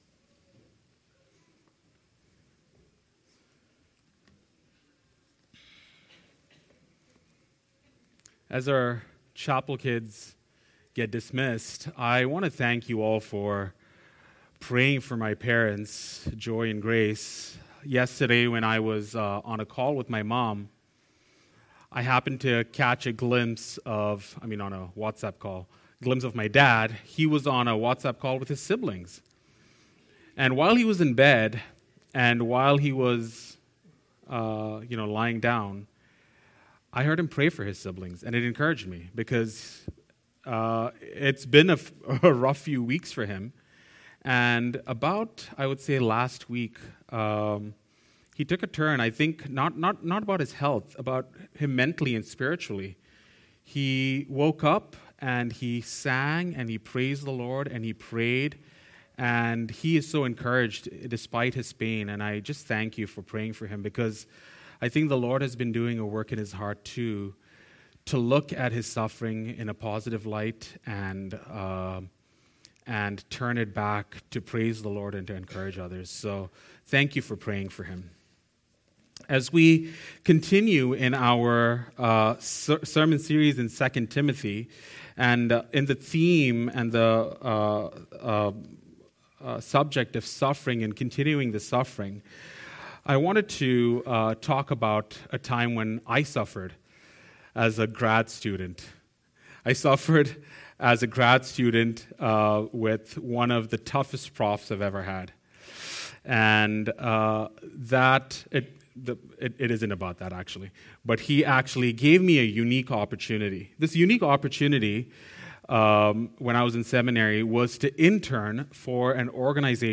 Westmount Bible Chapel, a Bible based, Christ-centred, Christian Bible church in Peterborough, Ontario committed to bringing truth by expository preaching.
Pulpit